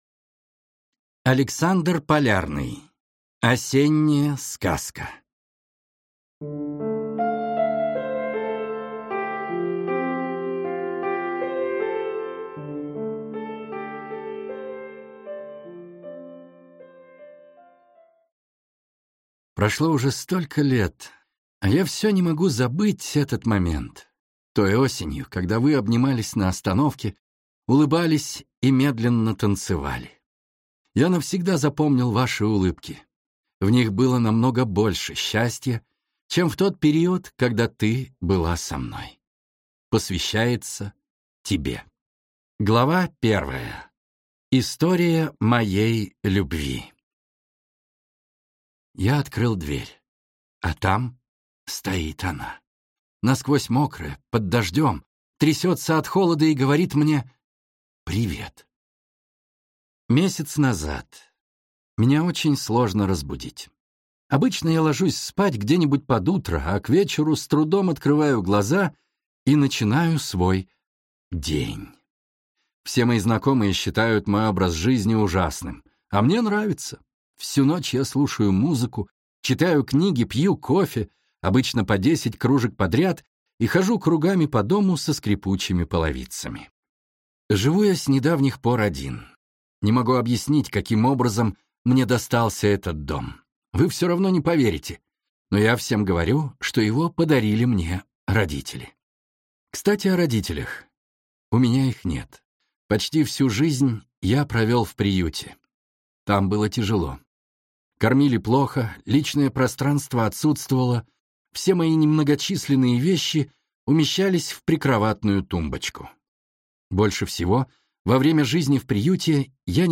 Аудиокнига Осенняя сказка | Библиотека аудиокниг
Прослушать и бесплатно скачать фрагмент аудиокниги